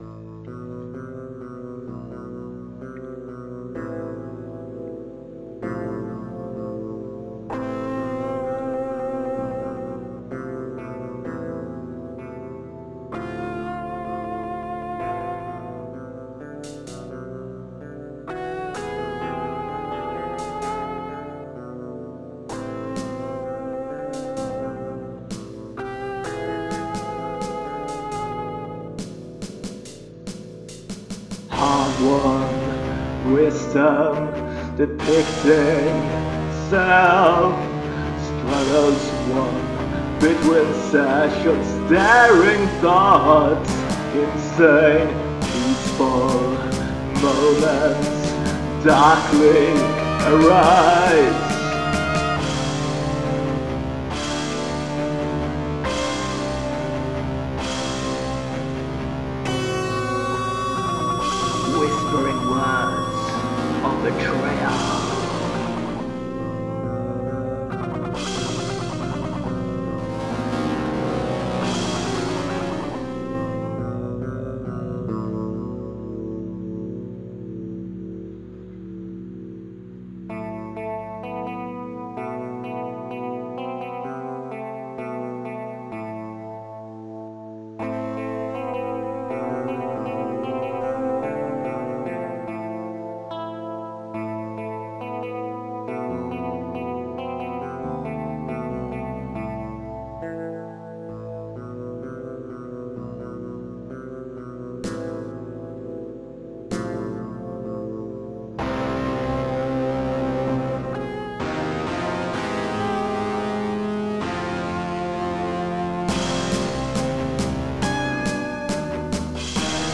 A new heavy metal epic